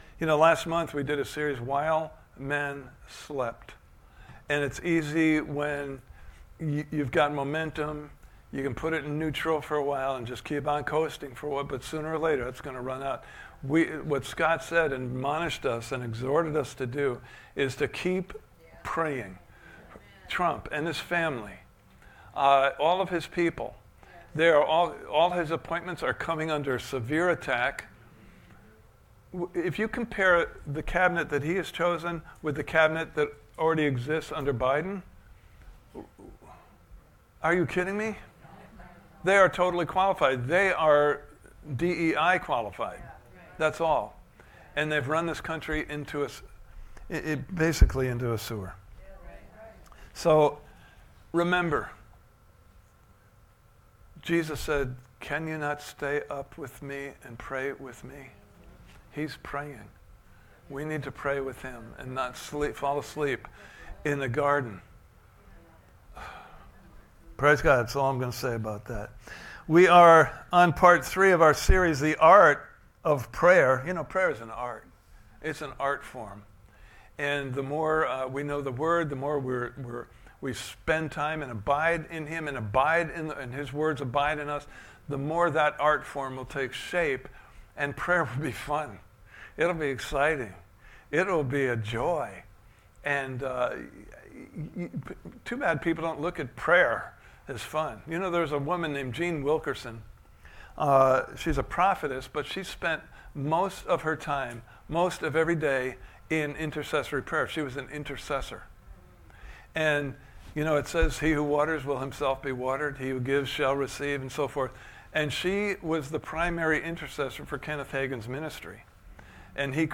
The Art of Prayer Service Type: Sunday Morning Service « Part 2